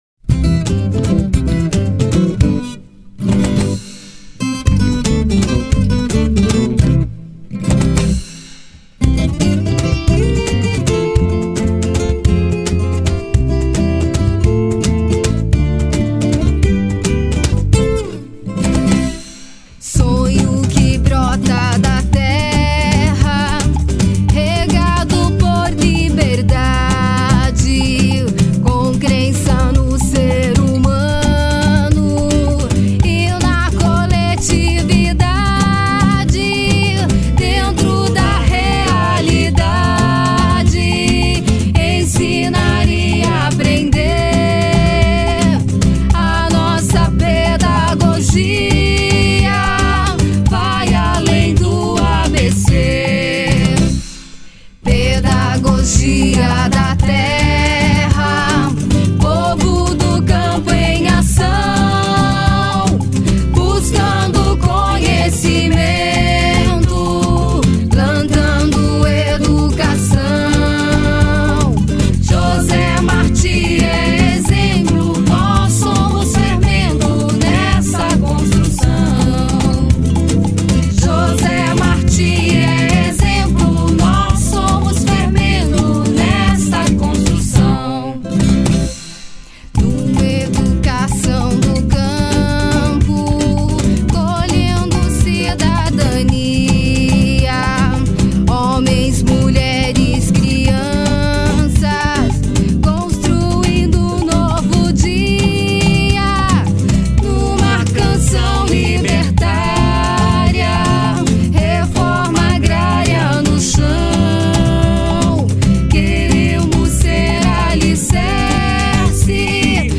03:45:00   Ciranda